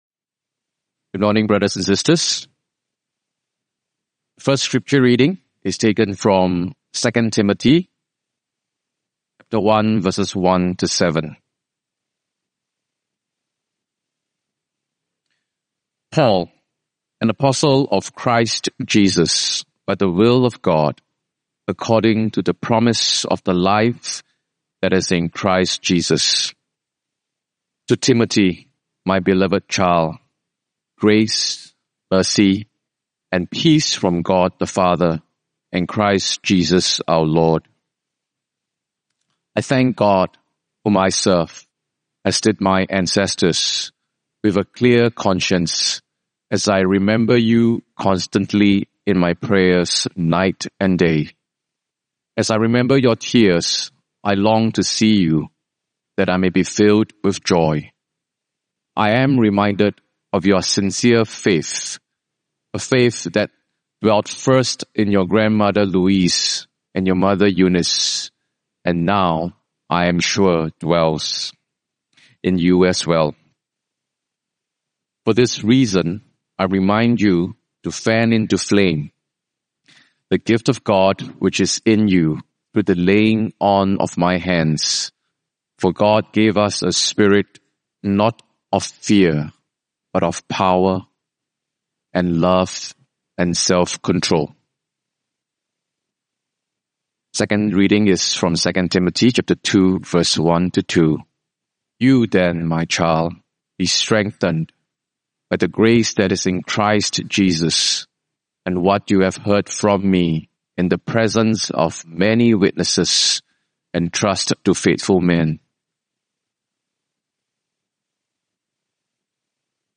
Sermon title: “Authentic Relationality” Scripture texts: 2 Tim 1:1-7; 2:1-2 Gospel text: John 1:1-5, 9-14